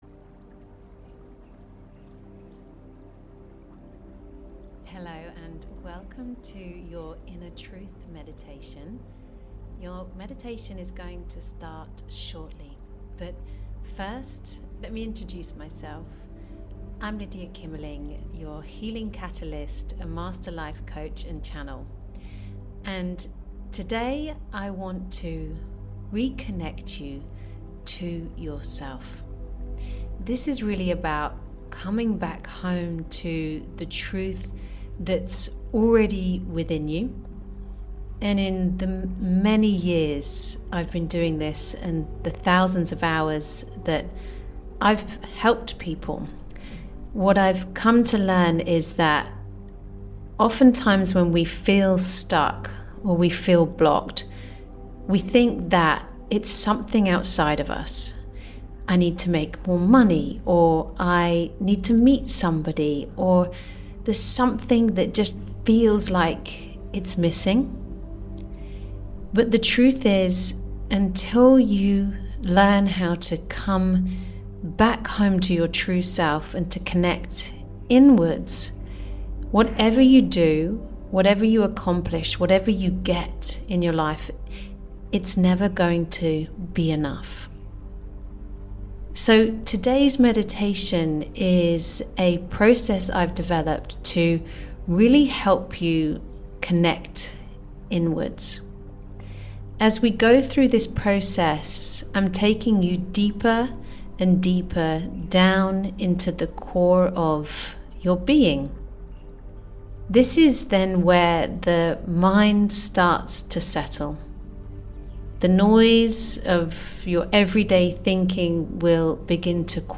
Inner Truth Meditation The ‘Inner Truth’ Meditation is a guided practice designed to quiet the mind, clear emotional noise, and reconnect you to your highest self. In just a few minutes, it helps you drop out of confusion and into clarity—so you can hear your own wisdom and feel what’s true for you beneath the fear, doubt or overthinking.